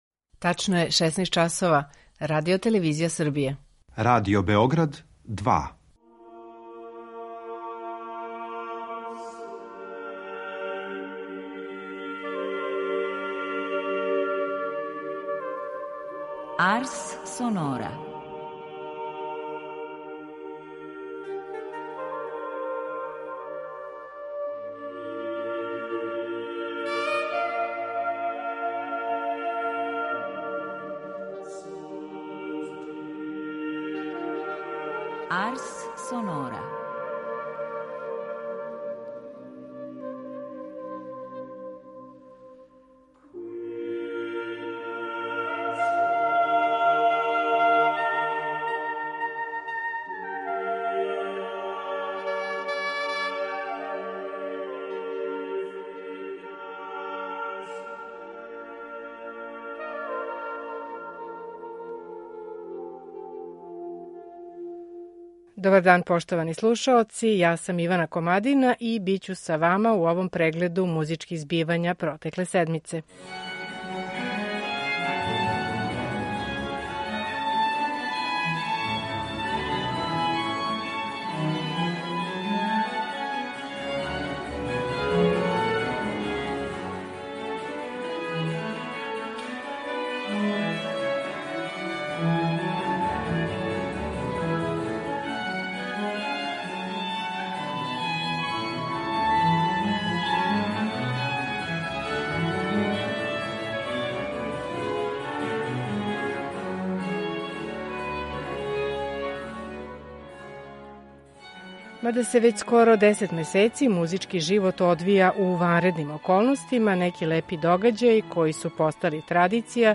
Стотину двадесету годишњицу смрти Ђузепа Вердија обележићемо дуетима из опере „Отело".